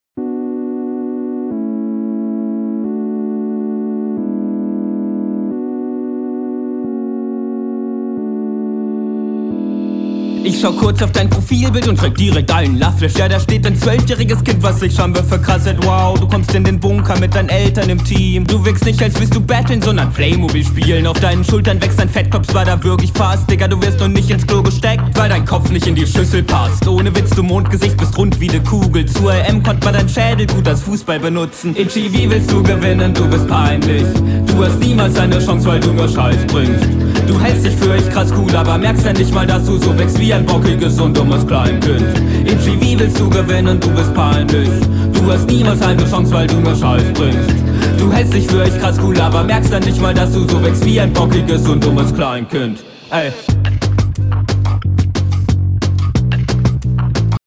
➨ Pattern gefallen mir wieder mal sehr gut mit schönen Abwechslungen.